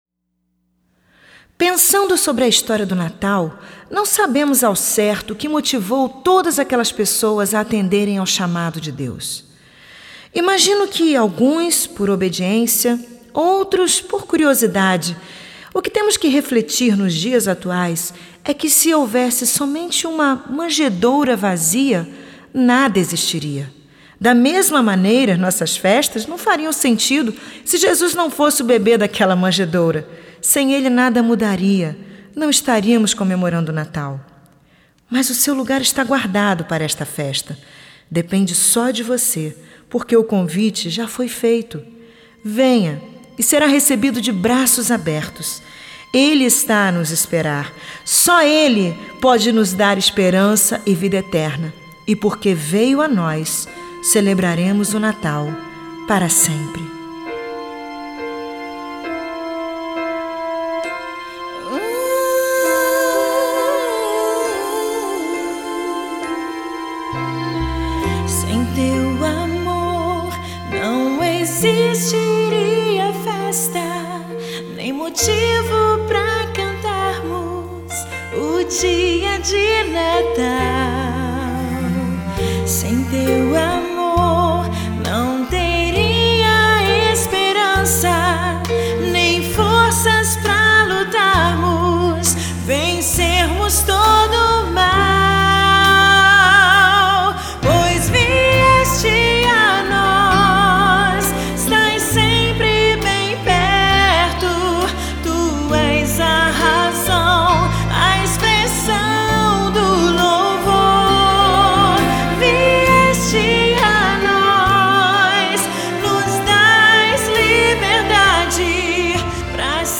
sua próxima cantata de Natal.